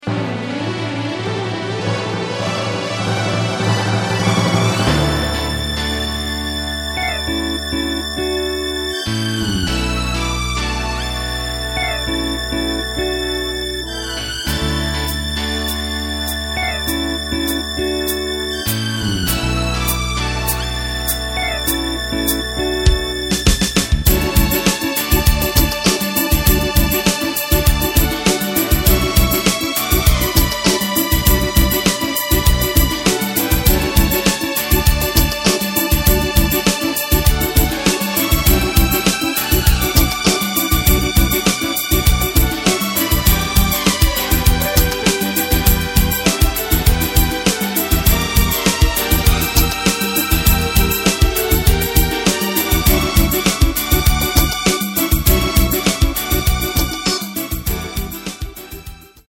Takt:          4/4
Tempo:         100.00
Tonart:            D
Instrumental aus dem Jahr 1973!
Playback mp3 Demo